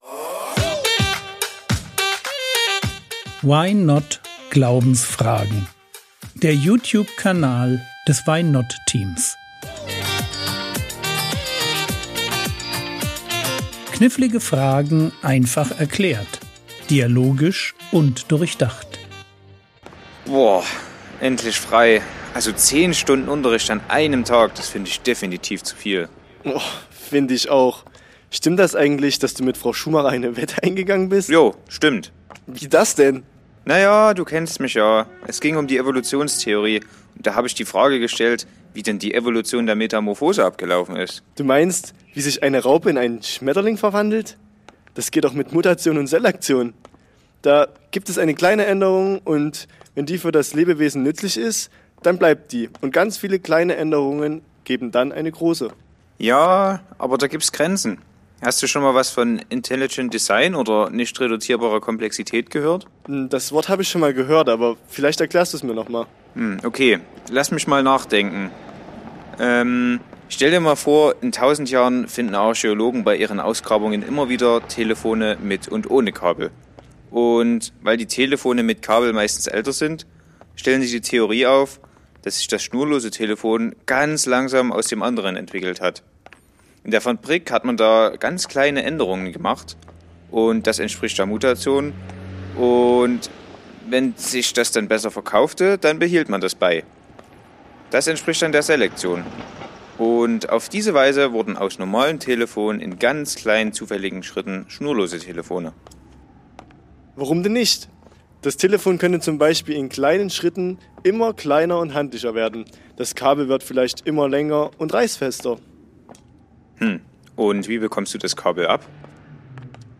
Intelligent Design ~ Frogwords Mini-Predigt Podcast